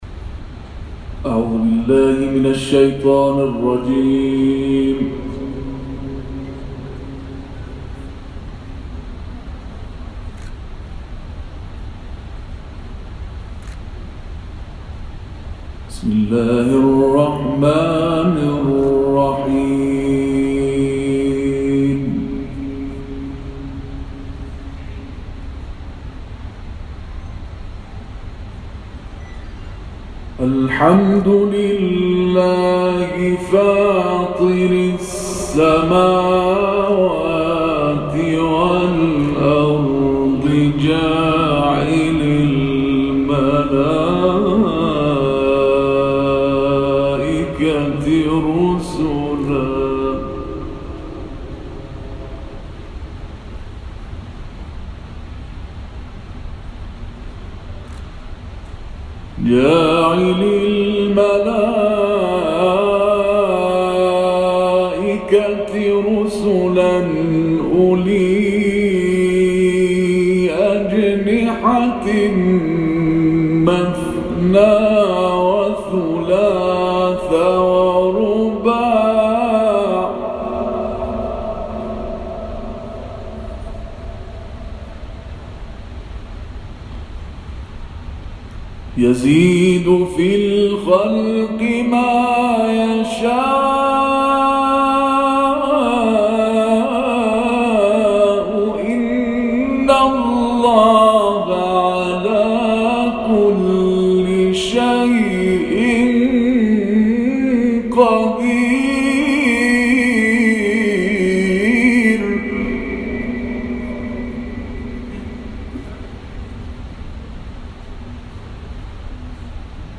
تلاوت
گروه فعالیت‌های قرآنی: محفل انس با قرآن کریم، روز گذشته دوم تیرماه در دانشگاه آزاد اسلامی کرمان برگزار شد.